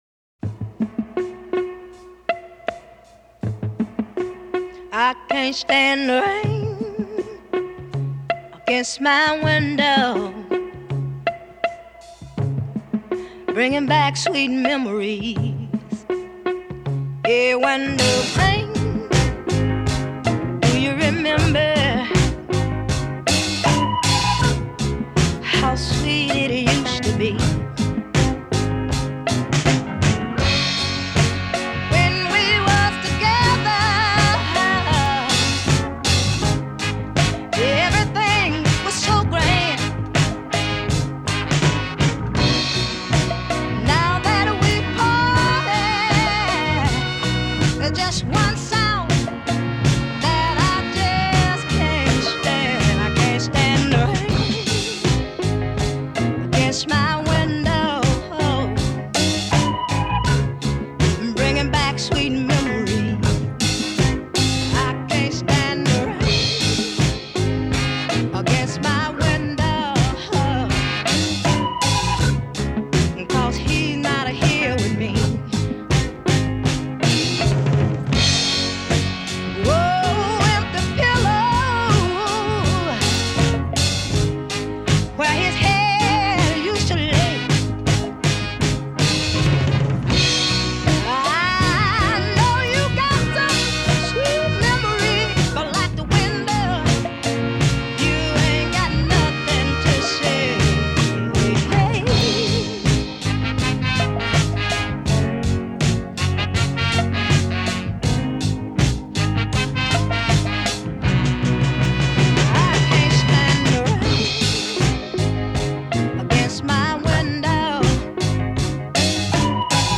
Tags1970s 1974 R & B soul Southern US summer